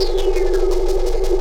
Index of /musicradar/rhythmic-inspiration-samples/170bpm
RI_ArpegiFex_170-04.wav